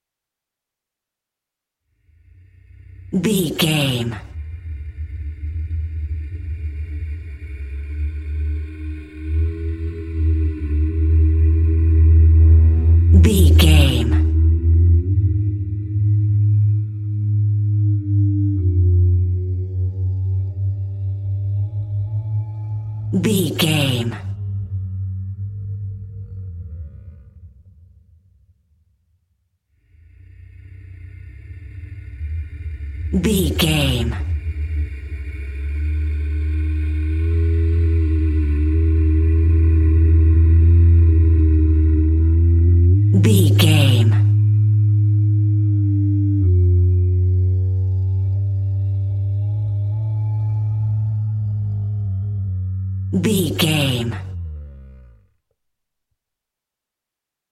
Dinosaur call whale close monster with without rvrb
Sound Effects
Atonal
ominous
eerie